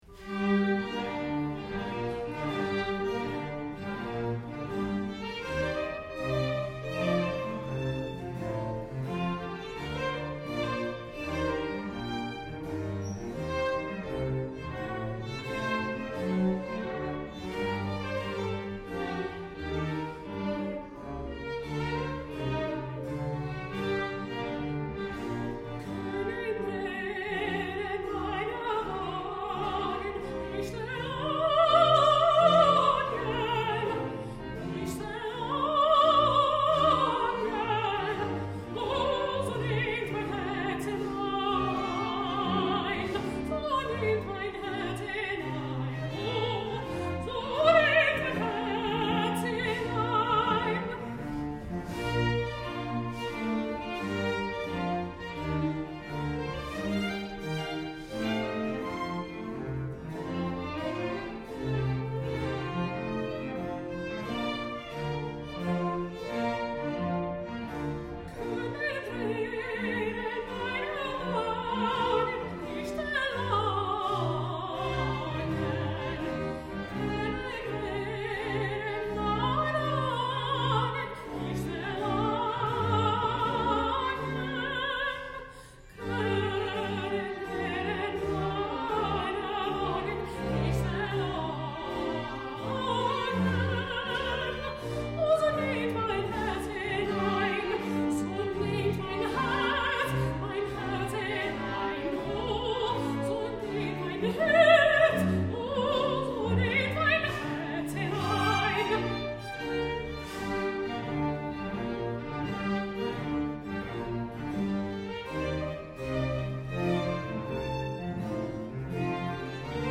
Bach alto arias